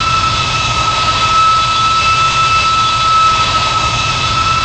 v2500-spool.wav